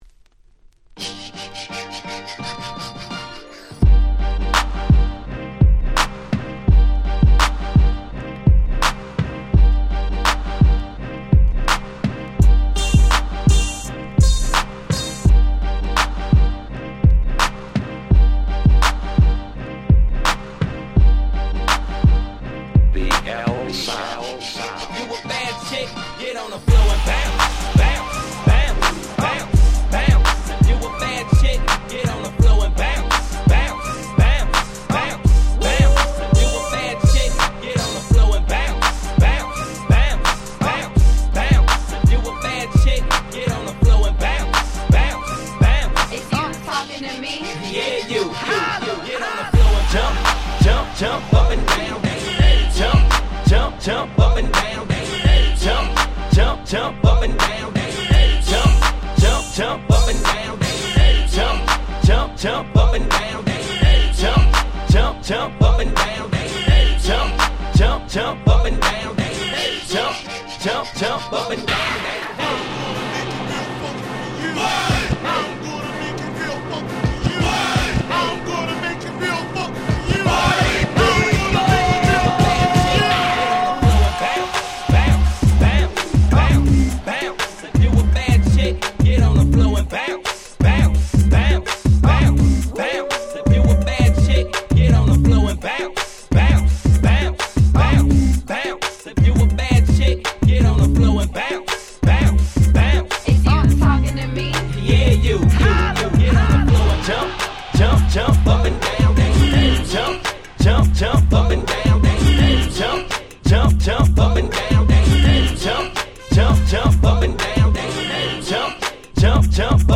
07' Nice Party Tracks / Mash Up !!
00's Hip Hop R&B